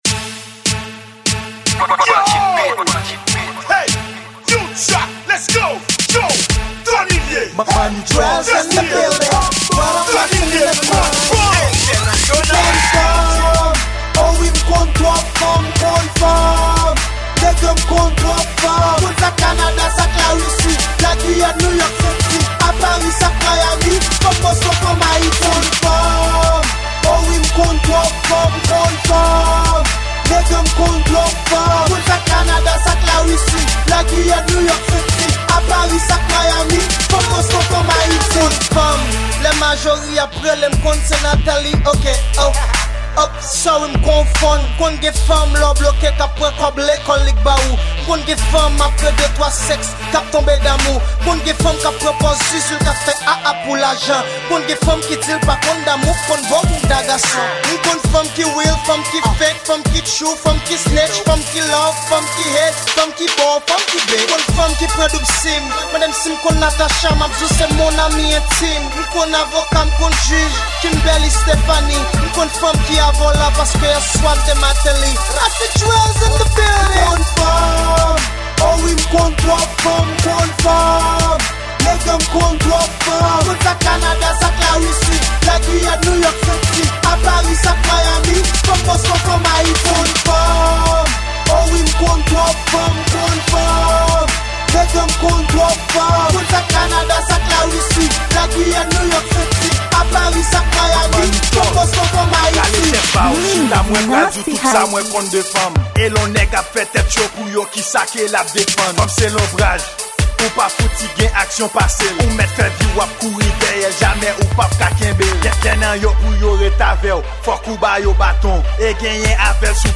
Genre: Hip Hop/Rap Kreyol.